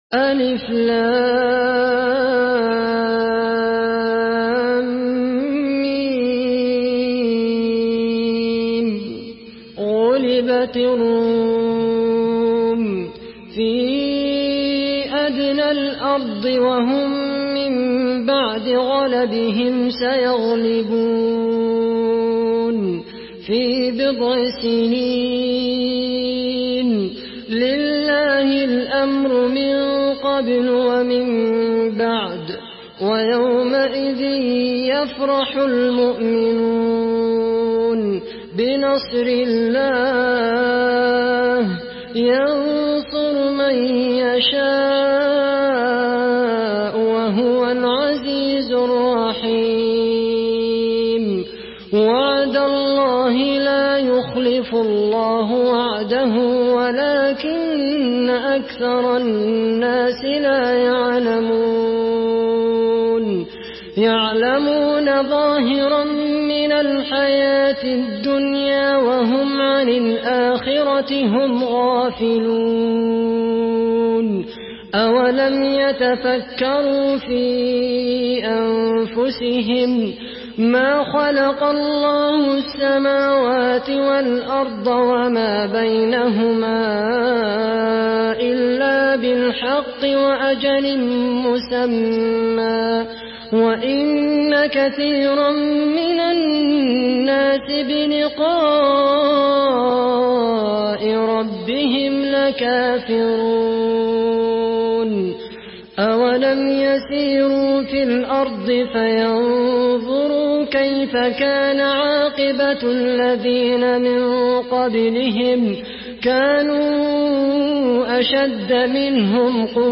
سورة الروم MP3 بصوت خالد القحطاني برواية حفص
مرتل حفص عن عاصم